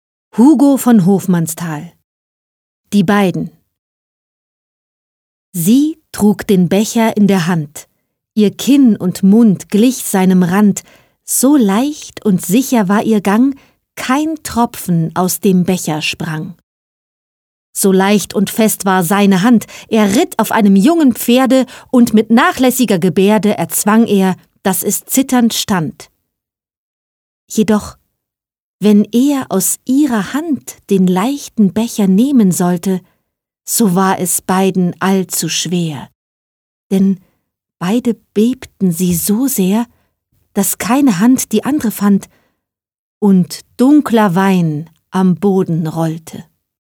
Lyrik, Schullektüre
Die Beiden – Gedicht von Hugo von Hofmannsthal (1874-1929)